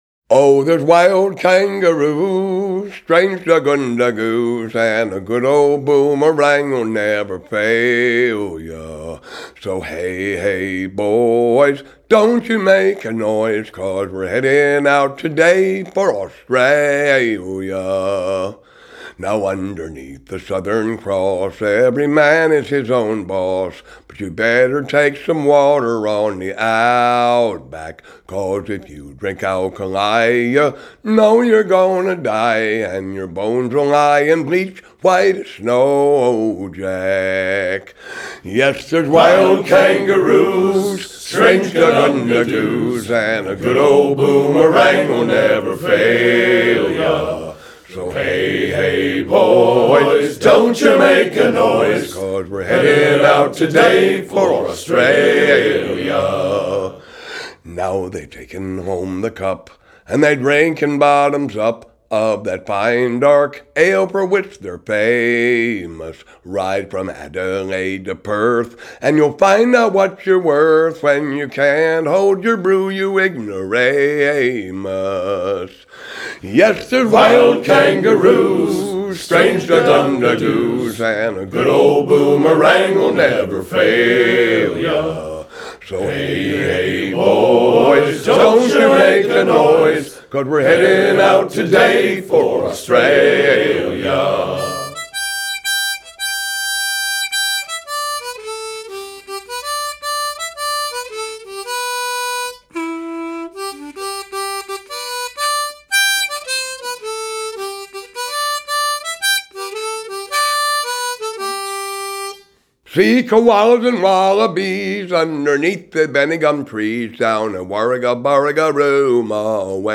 vocals/harmonica/slide guitar
drums/percussion
upright bass
electric guitar
electric bass
piano/clarinet/accordion/mandolin
fiddle